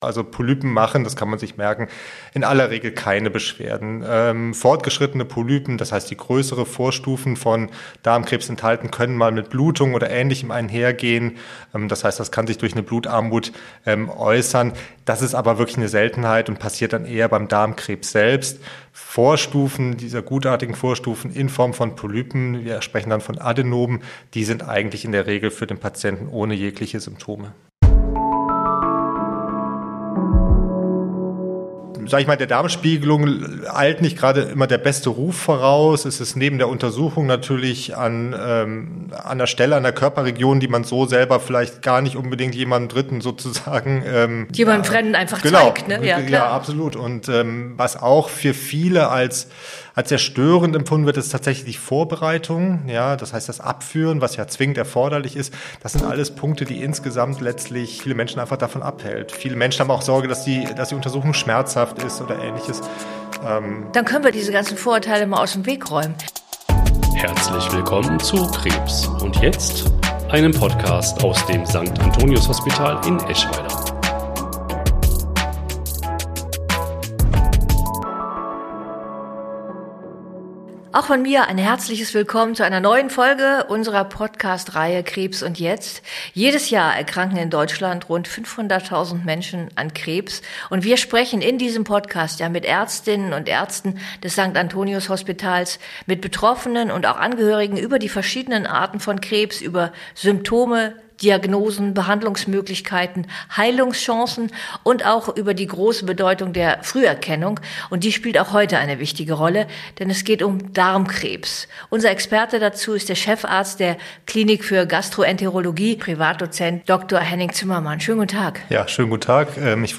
Im Gespräch mit Priv.-Doz.